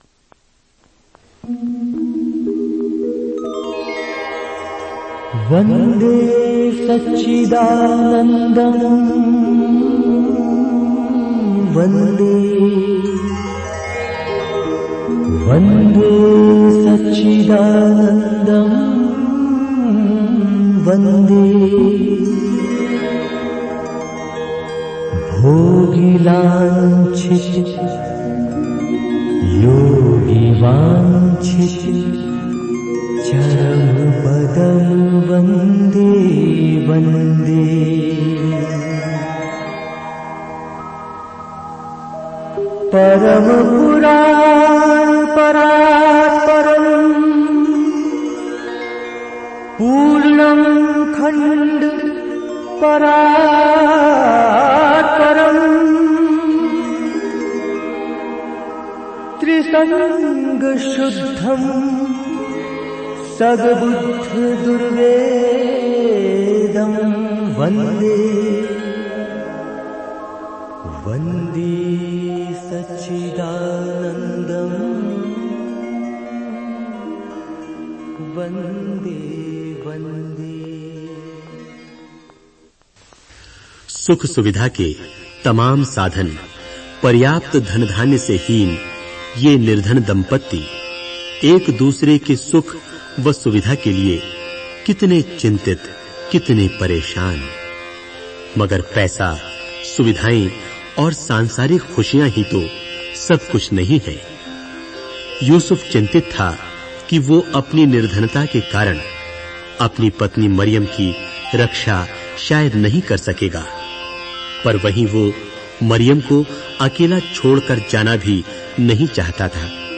Directory Listing of mp3files/Hindi/Bible Dramas/Dramas/ (Hindi Archive)